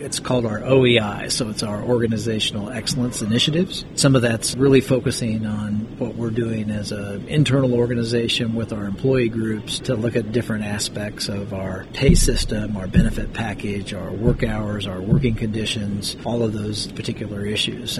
City Manager Ron Fehr explains what the OEI is.